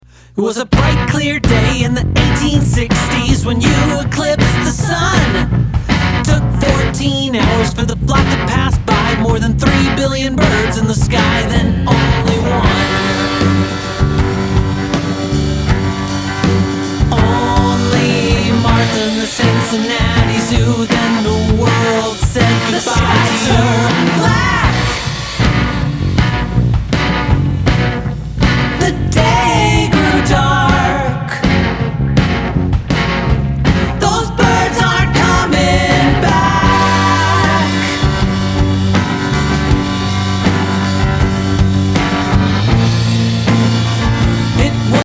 singable tunes and danceable rhythms